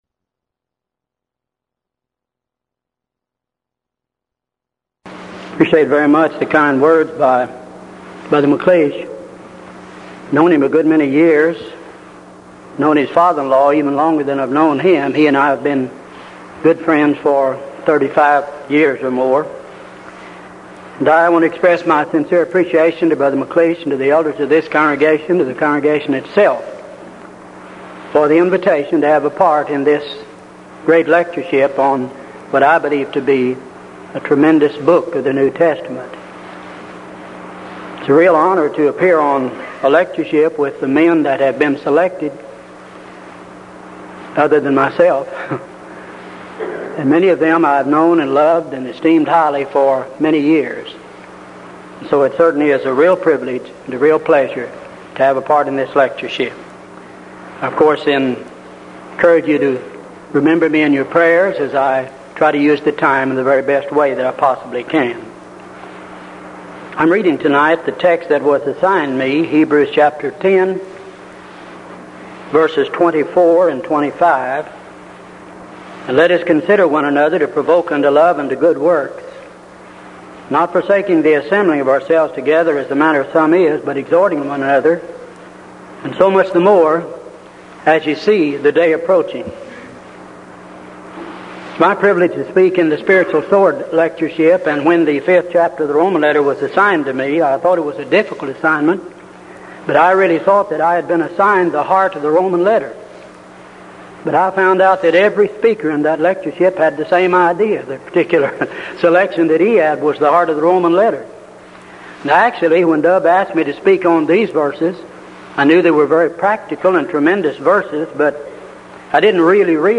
Event: 1983 Denton Lectures
lecture